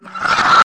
超级冷的啧啧声
描述：动画片啜饮
Tag: 卡通 饮料 啜食 吸管